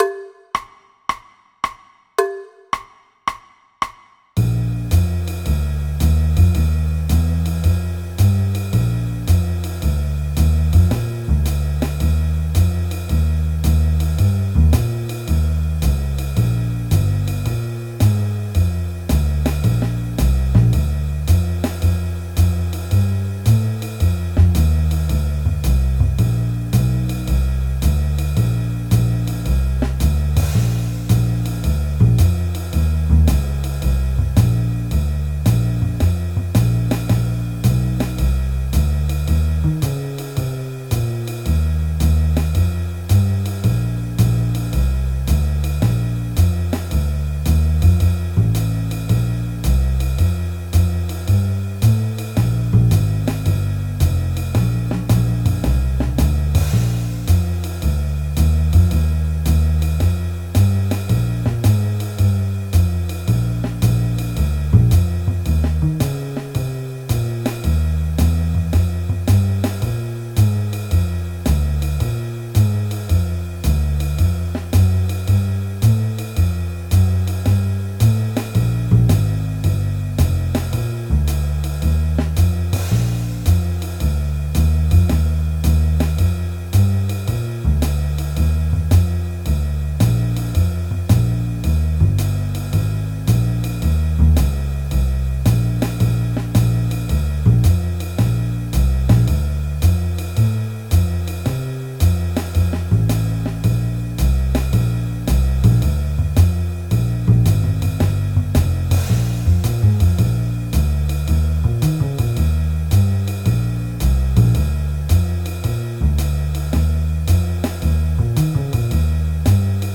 Backing Track (For Piano)